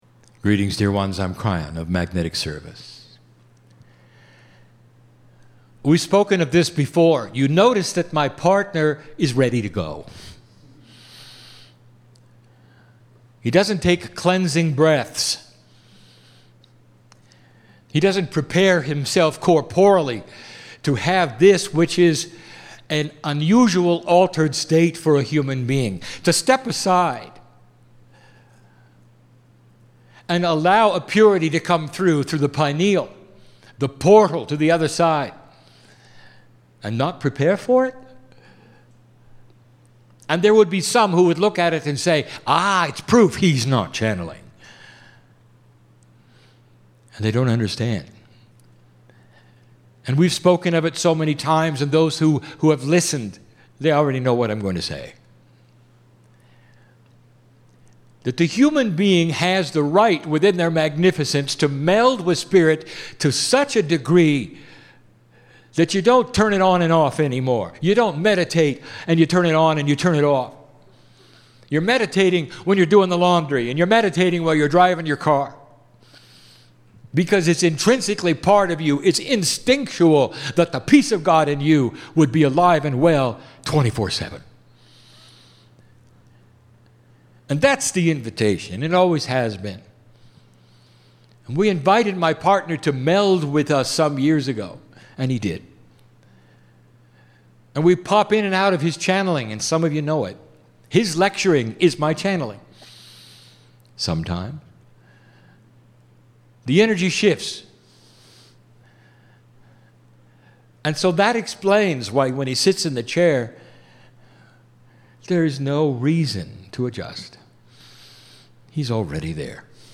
Back Santa Fe, New Mexico Sunday
2013 "We Are Here" Live Channelling